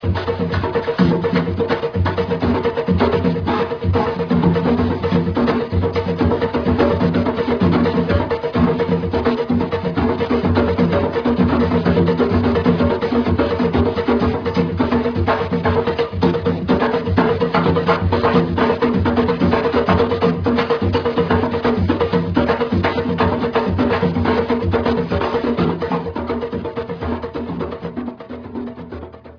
TAMBORILES
Batería de tambores de barril de una membrana.
Sus tamaños son distintos en cuanto a diámetro y altura, al igual que su sonoridad.
Se tocan siempre juntos y en número variable, durante las llamadas del Carnaval montevideano.
Ensamble: Batería: Chico, repique, piano y bajo o bombo
Característica: Toque de llamadas y salidas de carnaval. Acompañamiento del candombe, baile popular de tradición africana
Procedencia, año: Montevideo, Uruguay, 1974